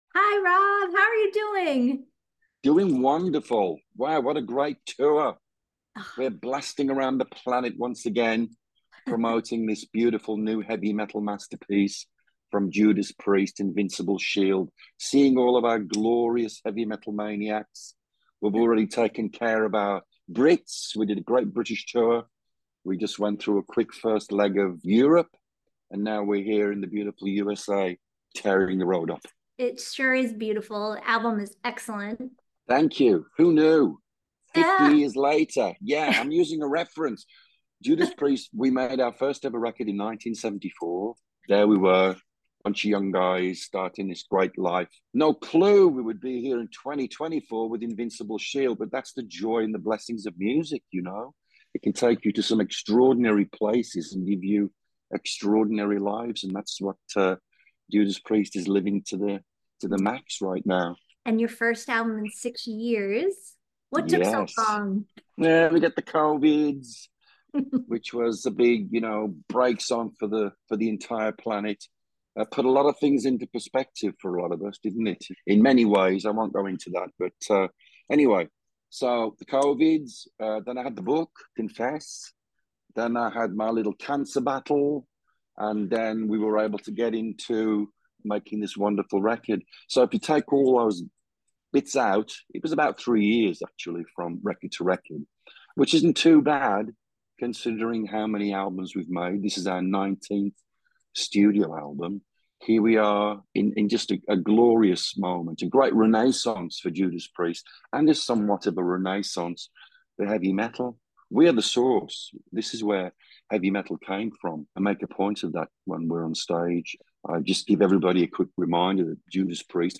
We sat down with the 72-year-old force of metal remotely to hear all about it.